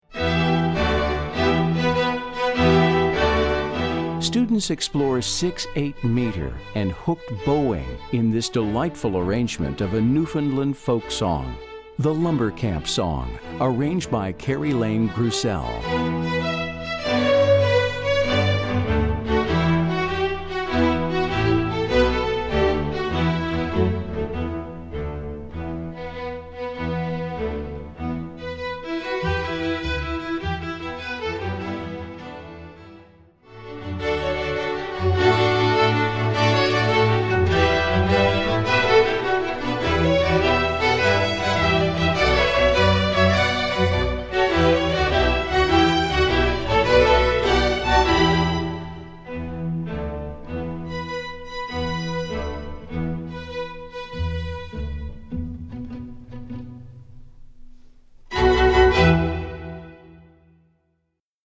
Composer: Newfoundland Folk Song
Voicing: String Orchestra